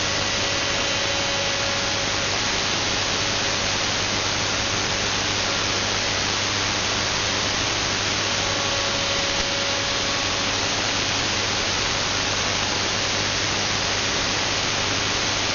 Oddity_280Hz_am.mp3